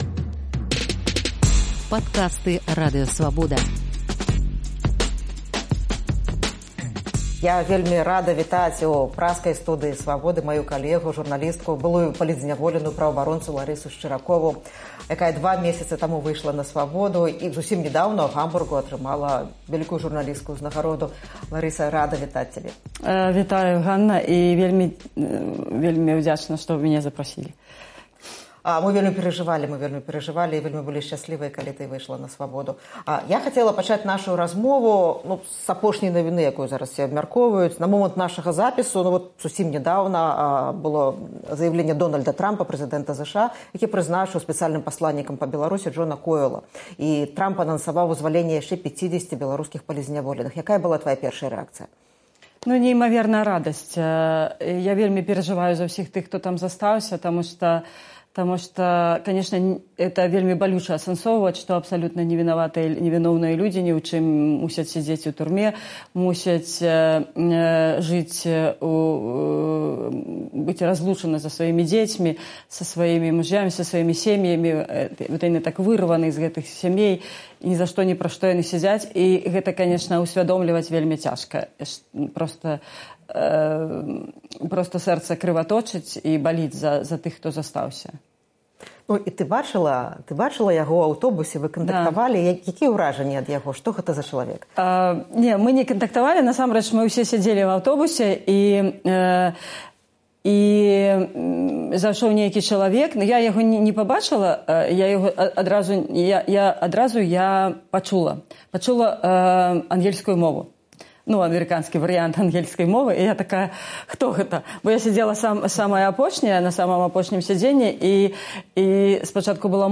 Вялікае інтэрвію